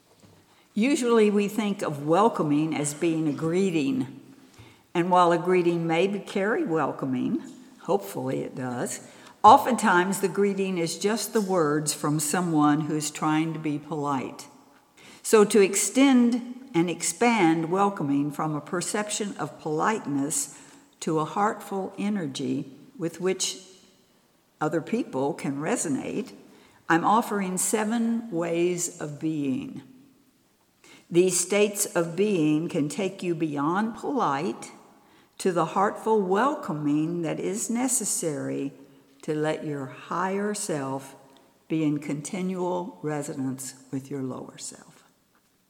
Workshop Recordings